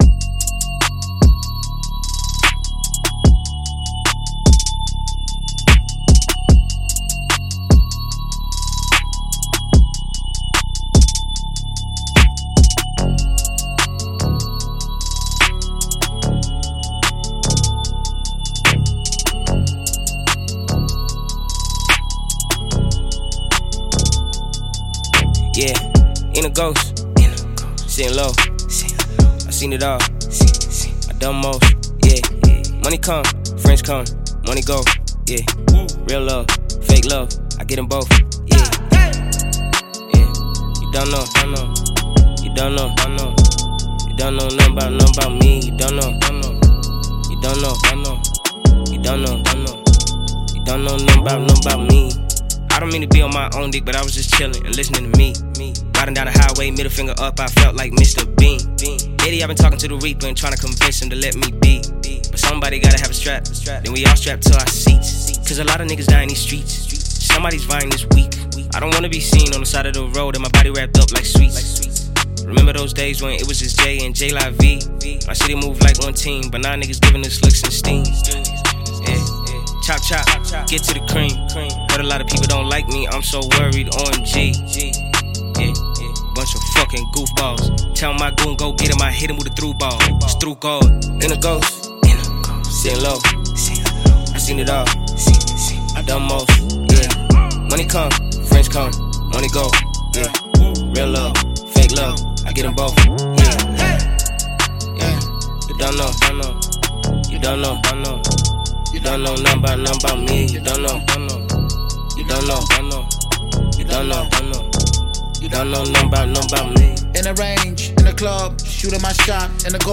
hip hop synergy